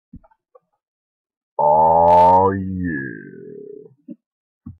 Tags: Freedom. DEEP. Nice